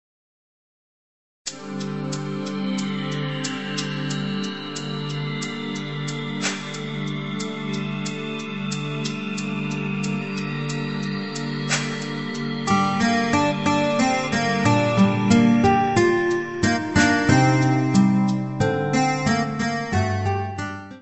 : stereo; 12 cm
Área:  Novas Linguagens Musicais